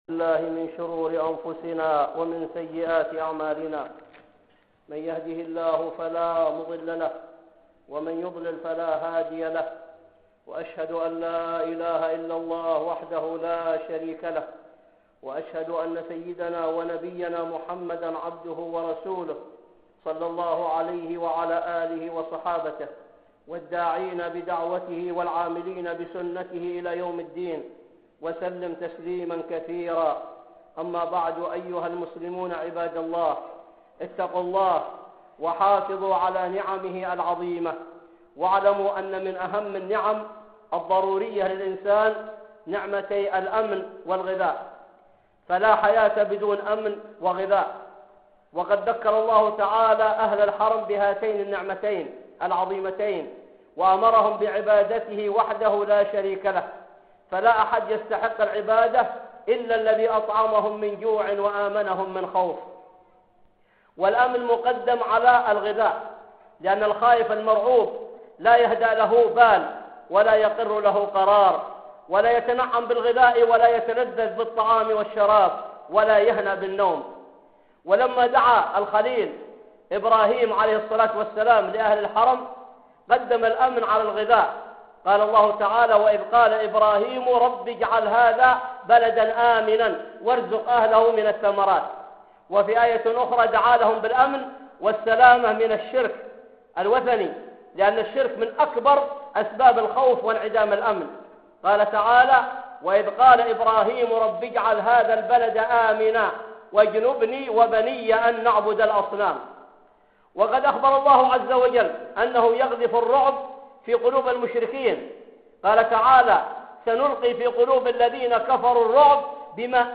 (خطبة جمعة) الأمن والغذاء